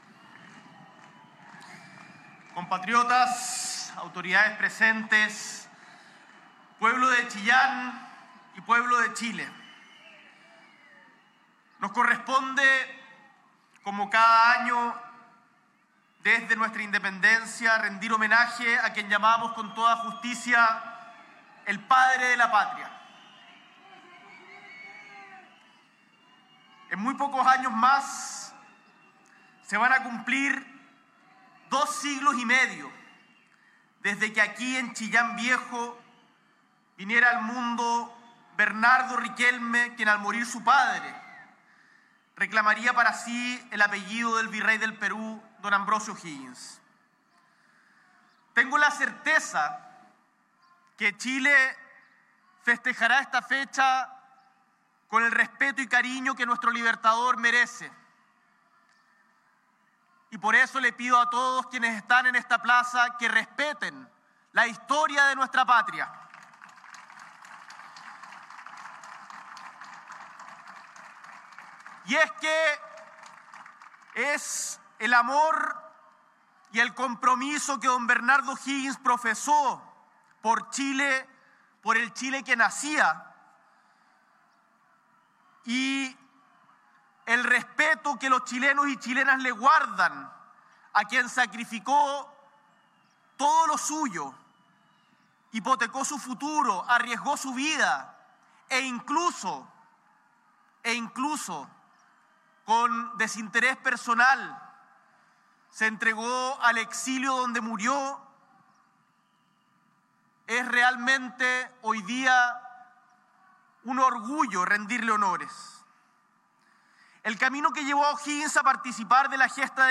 S.E. el Presidente de la República, Gabriel Boric Font, encabeza ceremonia de conmemoración de los 247 años del natalicio del Libertador Capitán General Bernardo O’Higgins Riquelme
En la tradicional ceremonia, realizada en el Parque Monumental Bernardo O’Higgins de Chillán Viejo, en la Región de Ñuble, el Mandatario destacó que “nos corresponde, como cada año, desde nuestra independencia, rendir homenaje a quien llamamos, con toda justicia, el Padre de la Patria. Y es que es el amor y el compromiso que don Bernardo O’Higgins profesó por el Chile que nacía y el respeto que los chilenos y chilenas le guardan a quien sacrificó todo lo suyo, es realmente hoy día un orgullo rendirle honores”.